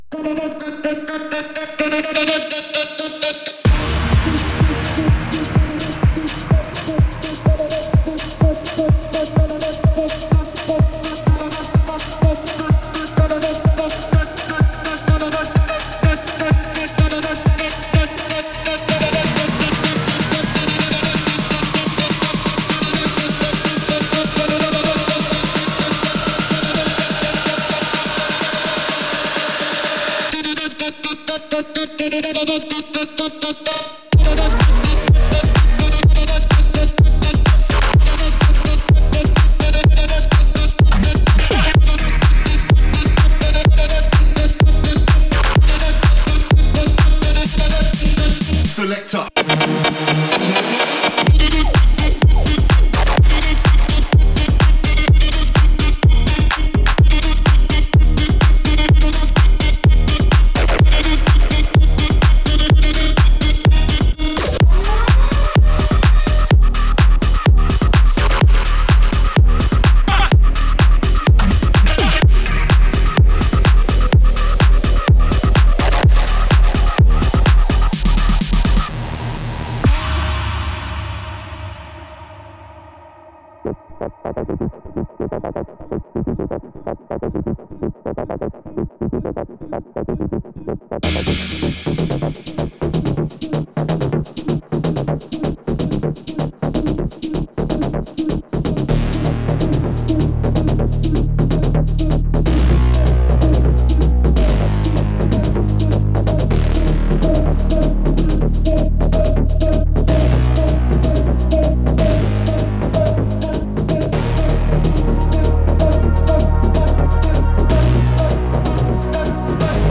Электронная Музыка